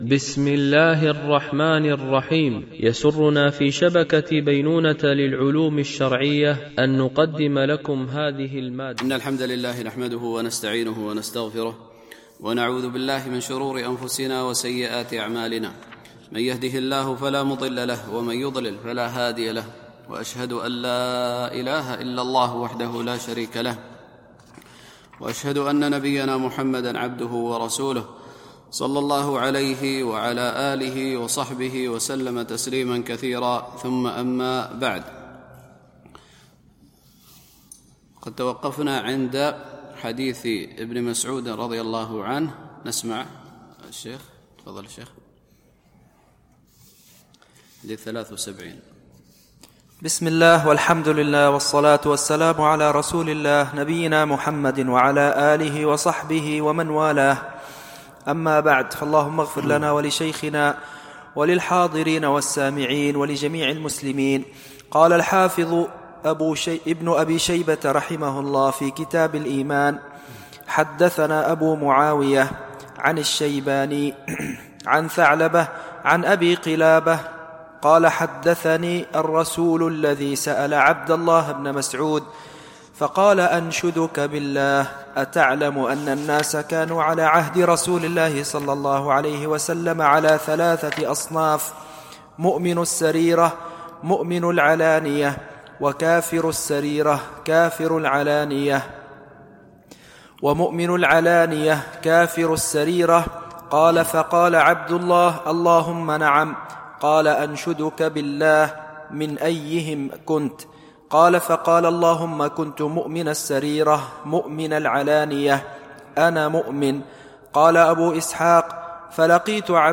التنسيق: MP3 Mono 44kHz 64Kbps (VBR)